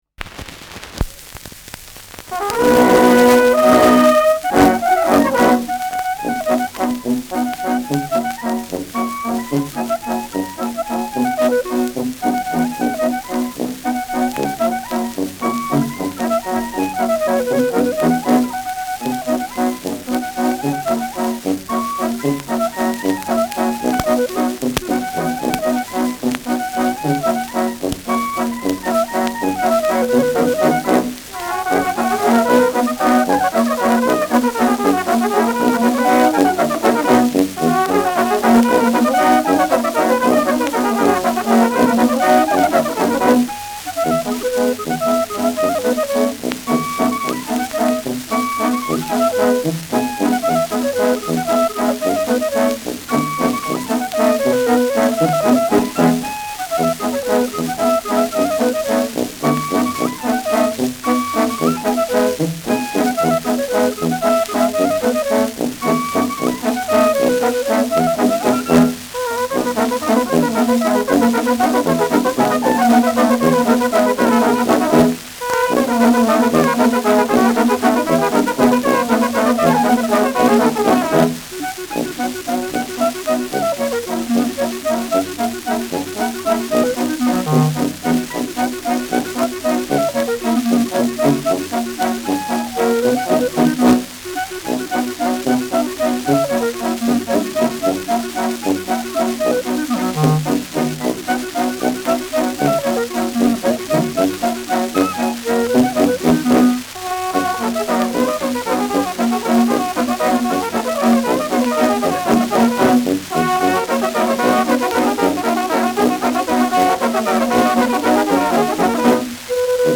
Schellackplatte
Abgespielt : Vereinzelt leichtes Knacken